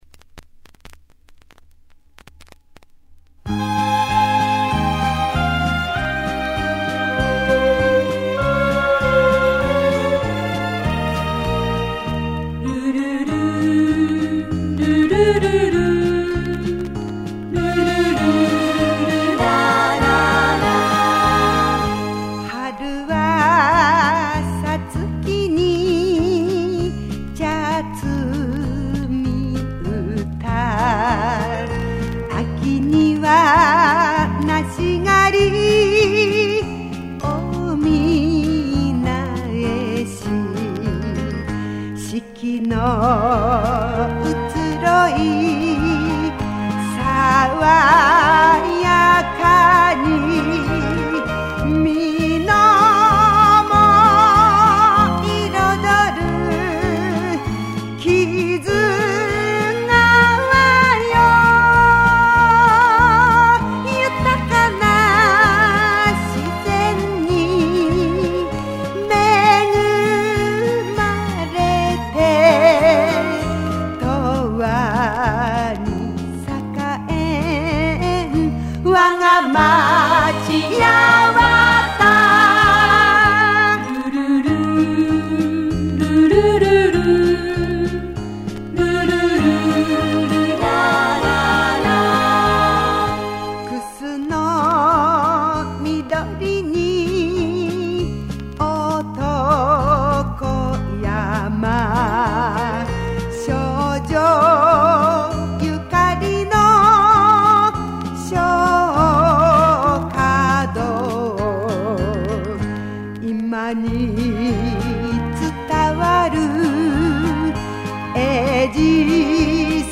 なお、下記の音声データは市文化協会音楽連盟合唱部会、市少年少女合唱団の皆さまの協力により再収録したものです。